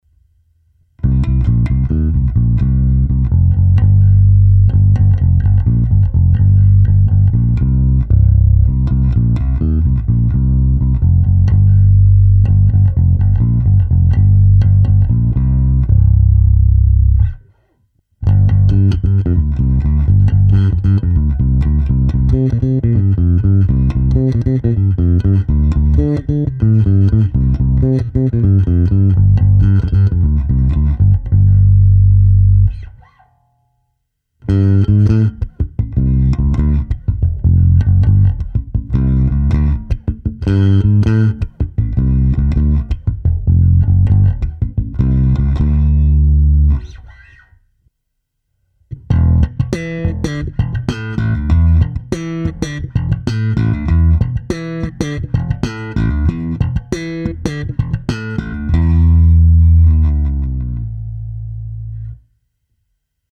Poslední ukázka je prohnaná simulací aparátu.
Ukázka na oba snímače se simulací aparátu